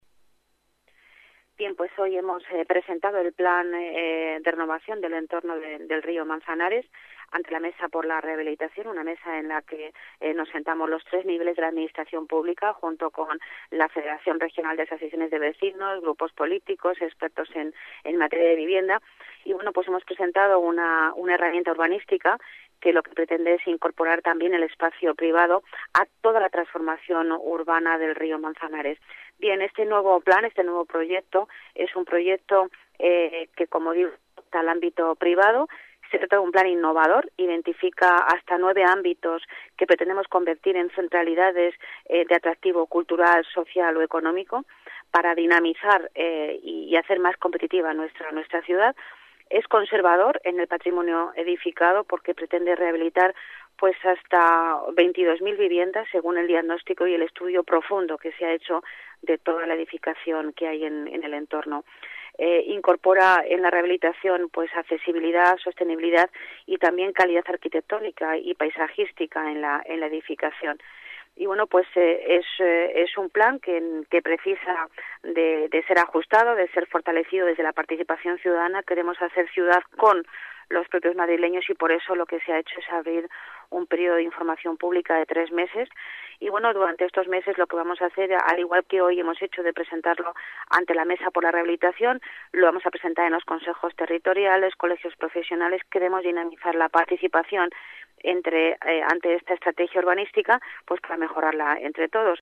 Nueva ventana:Declaraciones de Pilar Martínez, delegada de Urbanismo y Medio Ambiente